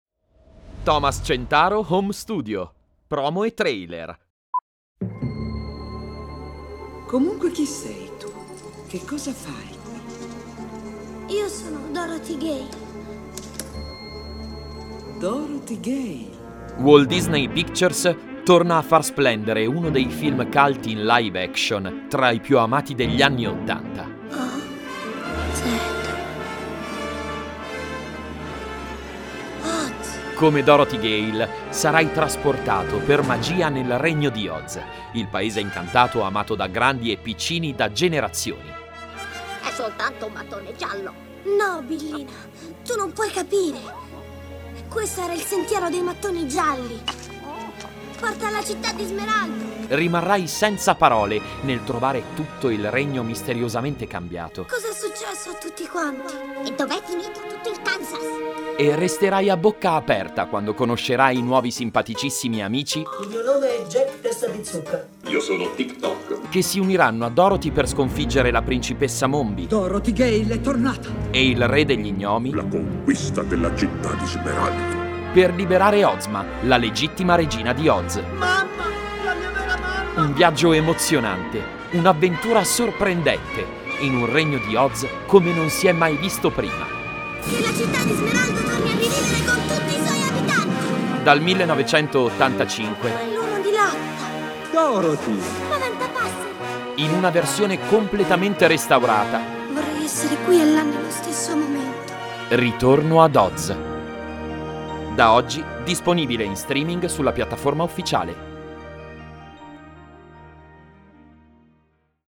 ITALIAN MALE VOICE OVER ARTIST
I own the latest technology equipment wrapped in a soundproof environment for high quality professional recordings that guarantee a result in line with the prestige of the brand to be promoted.
MOVIE TRAILER